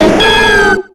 Cri de Méganium dans Pokémon X et Y.